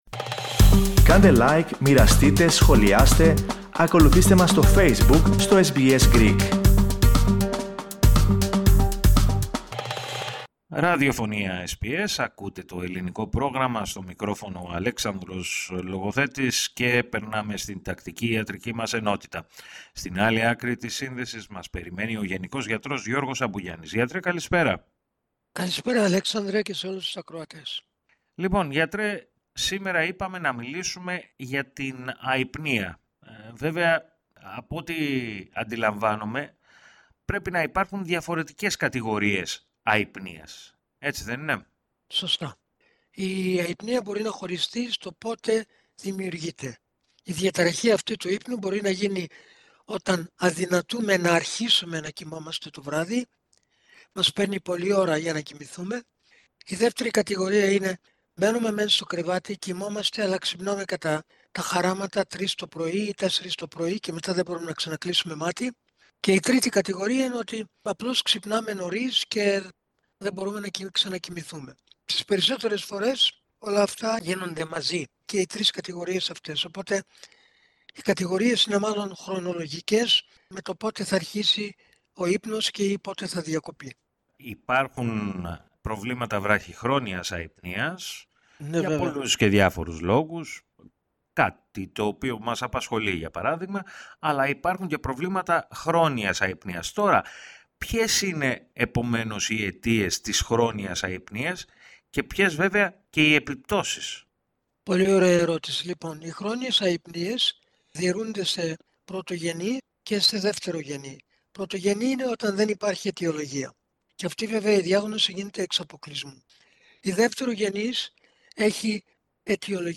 Ο γενικός γιατρός